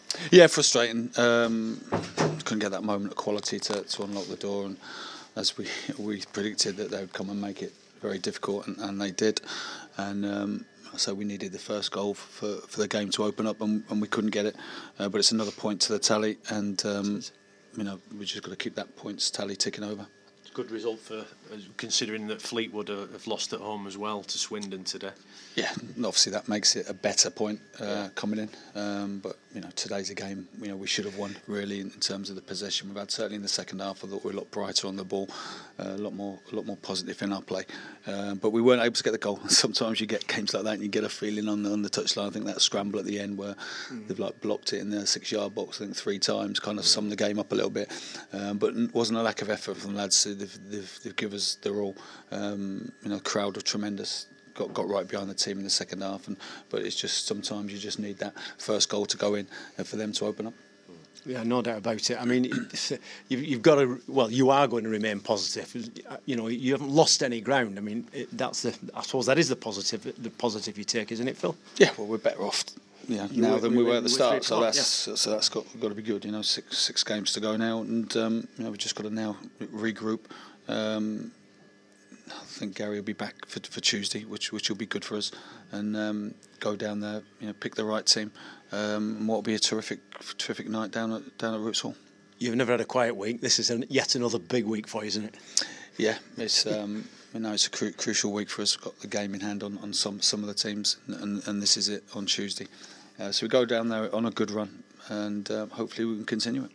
Bolton Wanderers manager Phil Parkinson has his say following the goalless draw with Chesterfield.